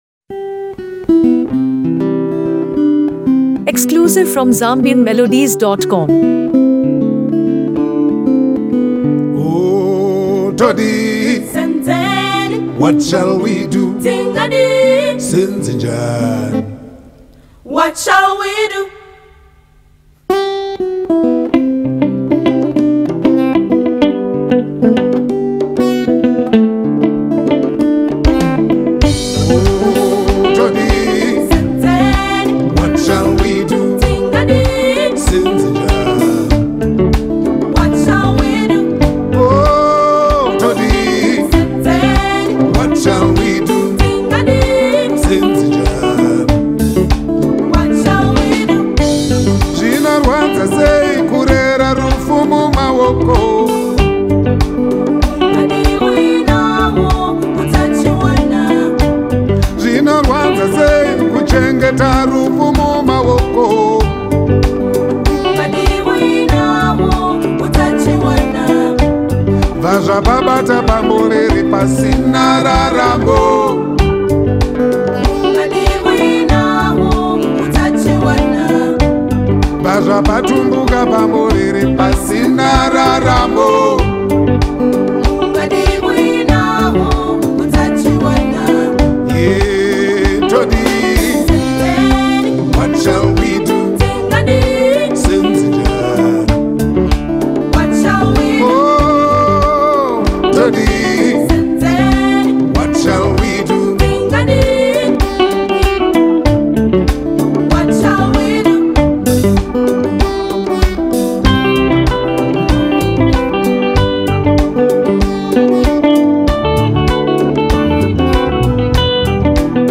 With his signature husky voice